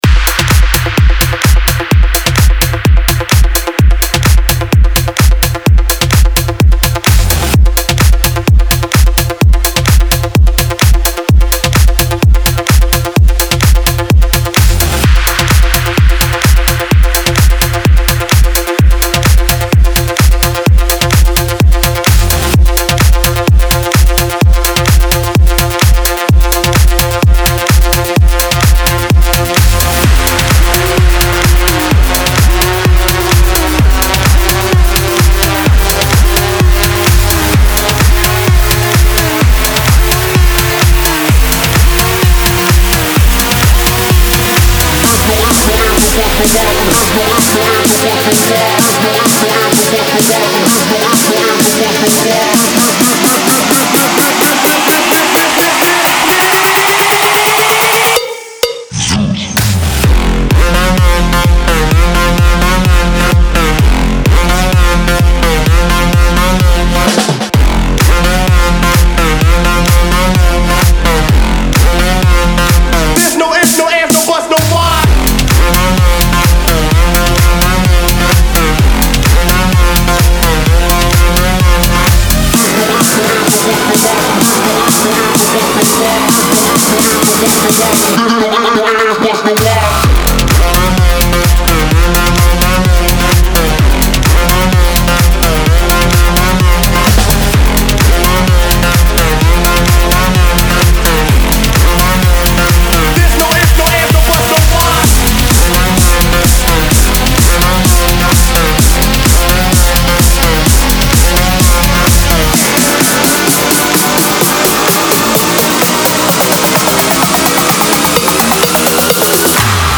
Категория: Электро музыка » Электро-хаус